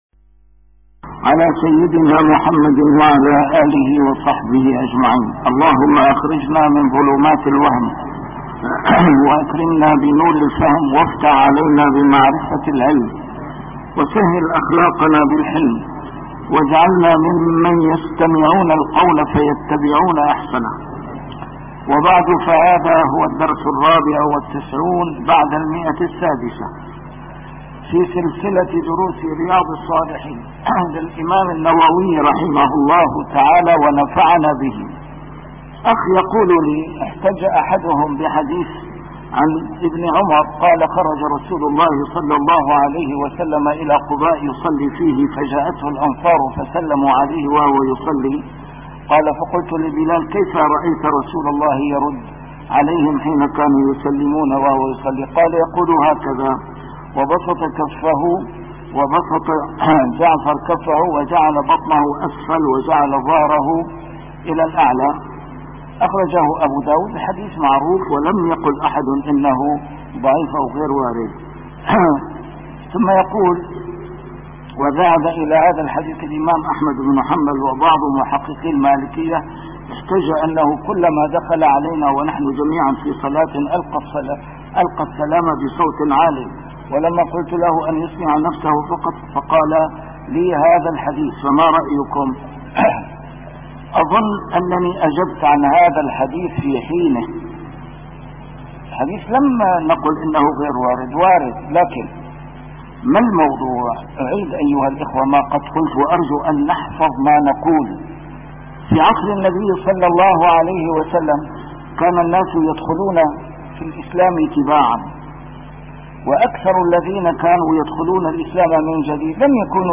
A MARTYR SCHOLAR: IMAM MUHAMMAD SAEED RAMADAN AL-BOUTI - الدروس العلمية - شرح كتاب رياض الصالحين - 694- شرح رياض الصالحين: يسمي المستأذن نفسه